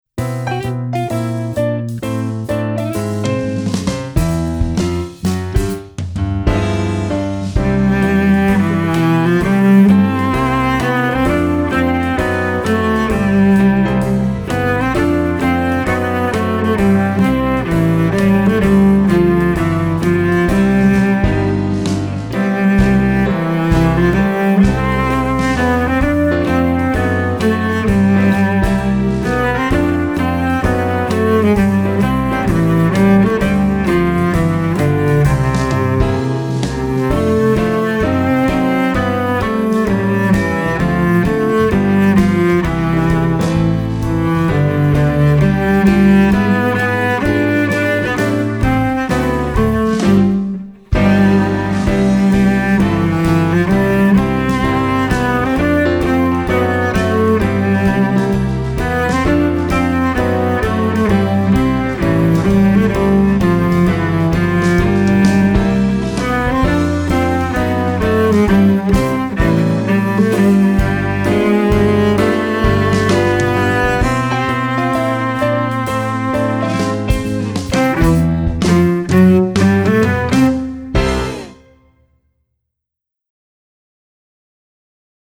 Voicing: Cello and Piano